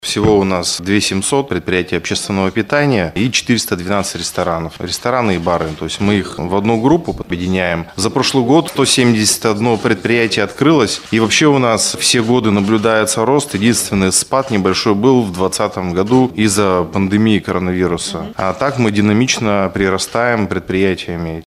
Каждый год число предприятий общественного питания в регионе растет. Сейчас их 2700, — рассказал директор городского департамента потребительского рынка и услуг Максим Чаплыгин на пресс-конференции «ТАСС-Урал».